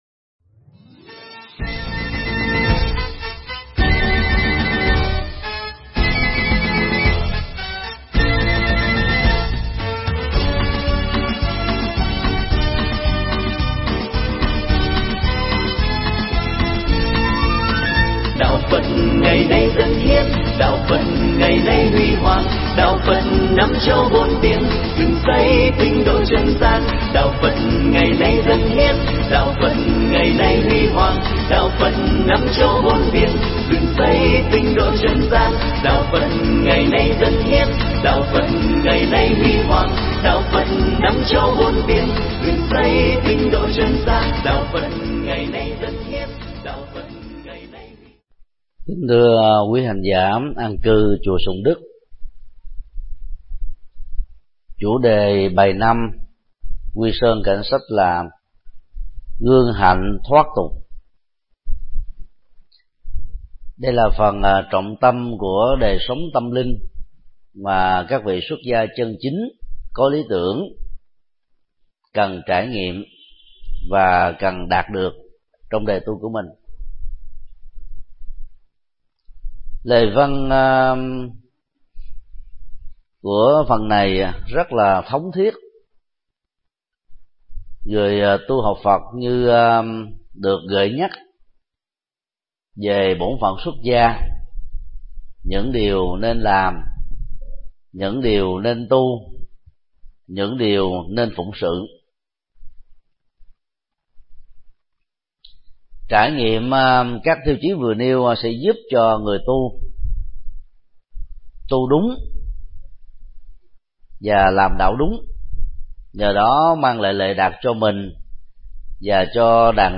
Mp3 Pháp Thoại Quy Sơn Cảnh Sách 05: Gương Hạnh Thoát Tục
Giảng tại trường hạ chùa Sùng Đức